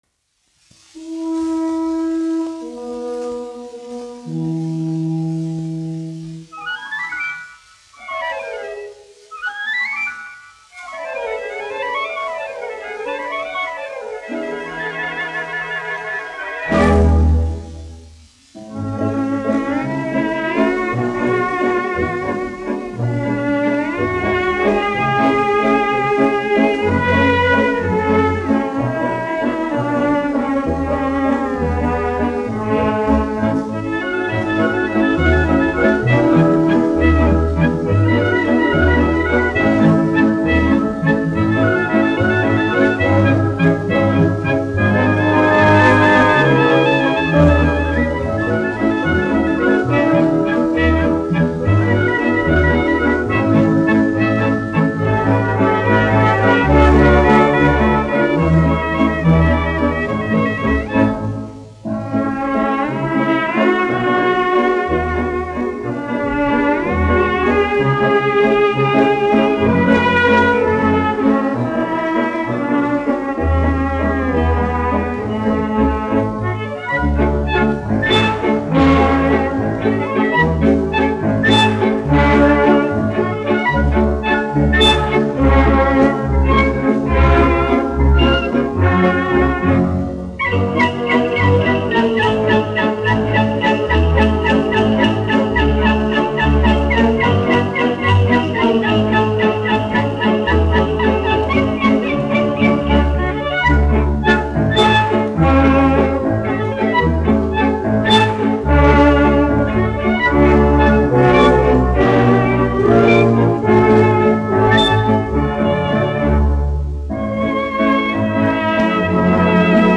1 skpl. : analogs, 78 apgr/min, mono ; 25 cm
Valši
Orķestra mūzika
Latvijas vēsturiskie šellaka skaņuplašu ieraksti (Kolekcija)